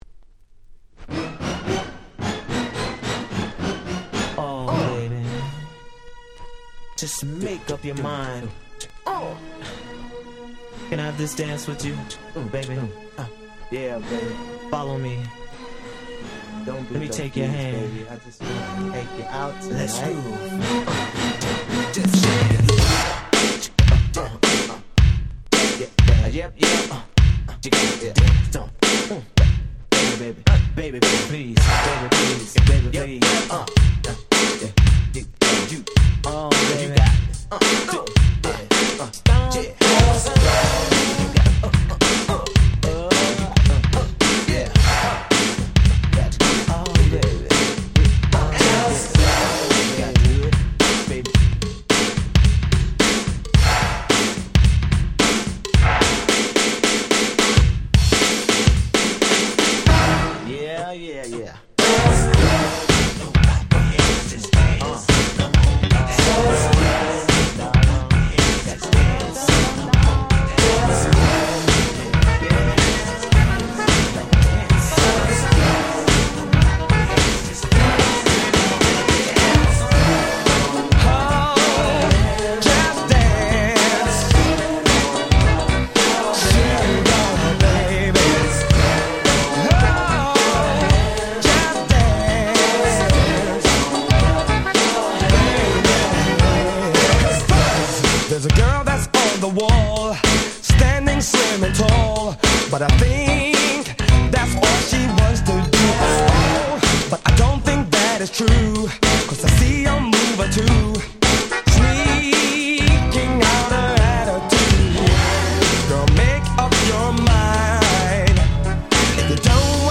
89' Smash Hit R&B / New Jack Swing !!
バッキバキのニュージャックナンバー！！
80's ニュージャックスウィング ハネ系